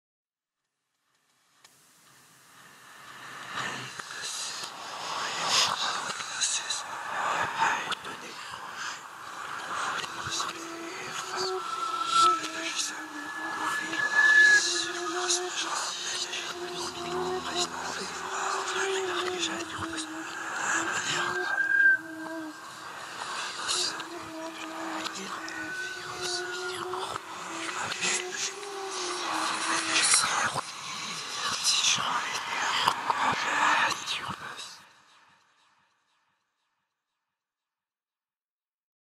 MURMURES-INQUIETANTS---Bruitage-Gratuit.mp3